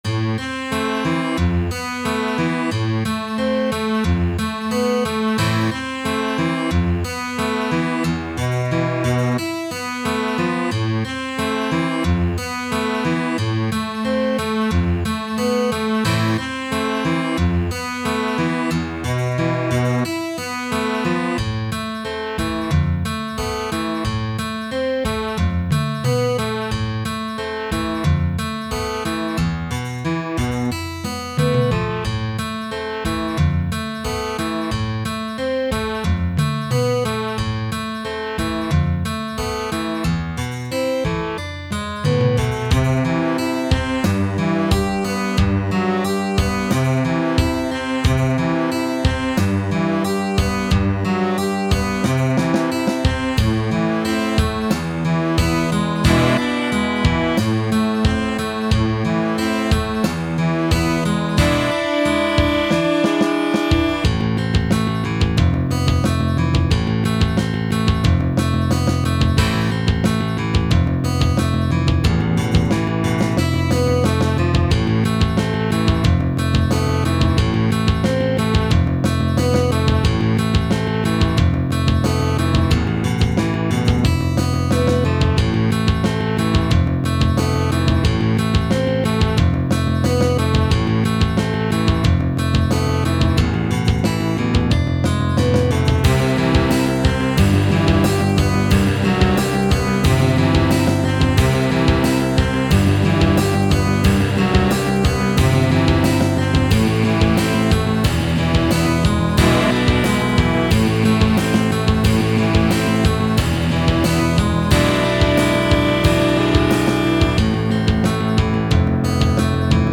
Відома пісня у новому моєму звучанні (без слів).